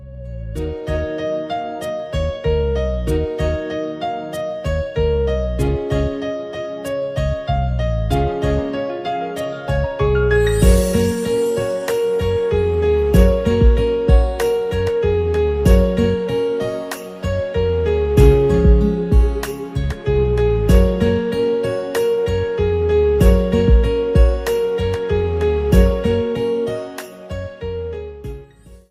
Category: Piano Ringtones